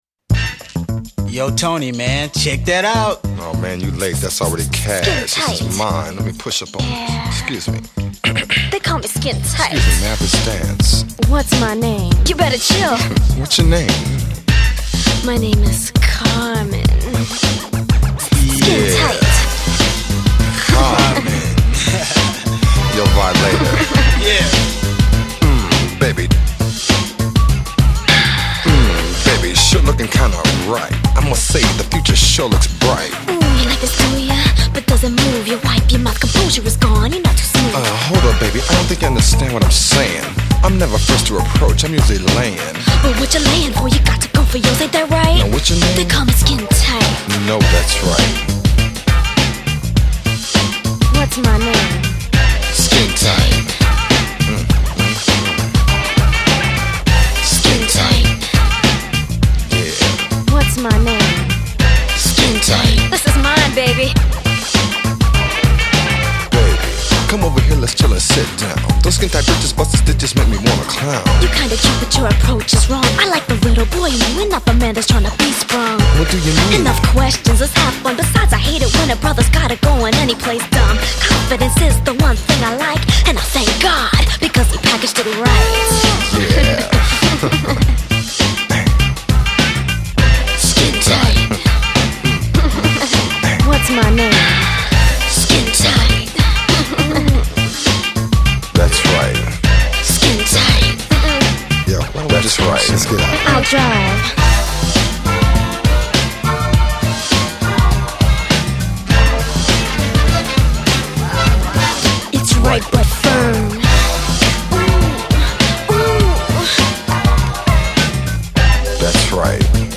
pop R&B
funky rap duet